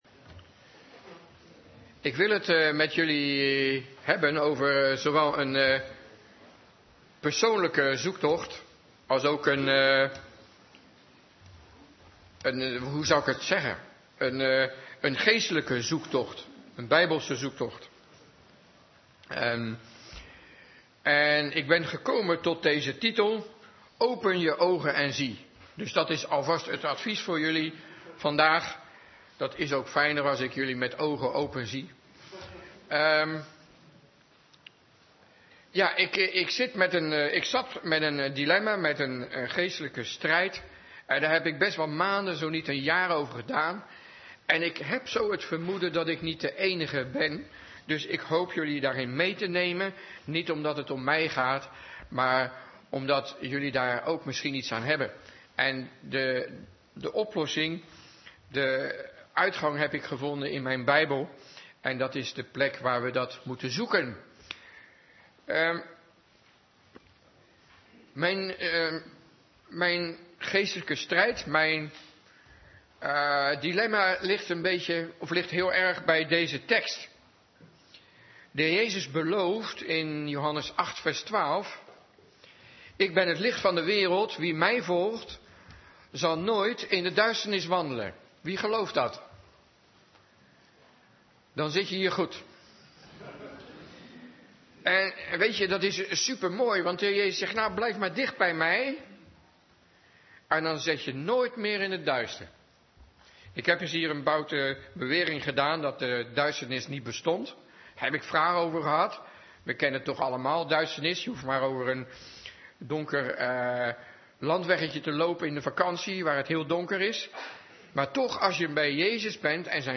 Toespraak van 2 februari 2020: Open je ogen en zie - De Bron Eindhoven